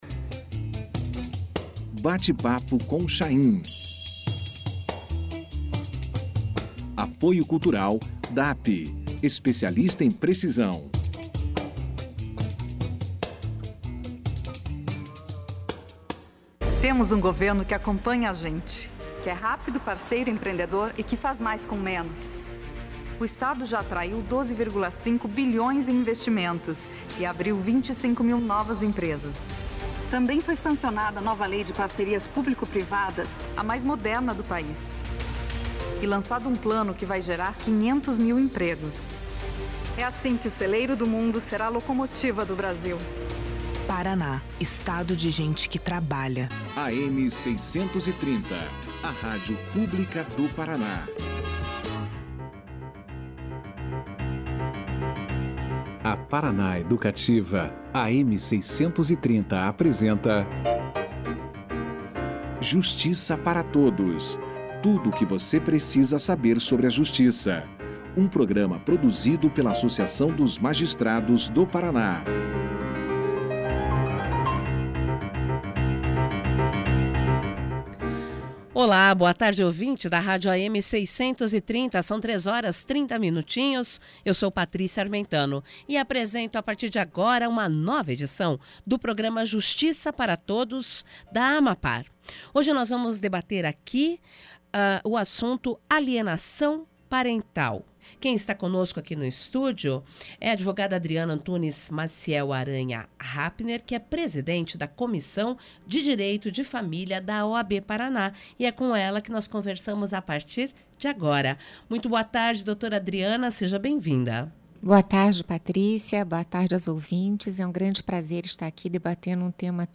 Alienação parental foi o tema debatido pelo programa de rádio da AMAPAR, Justiça para Todos, na segunda-feira (22/07/2019).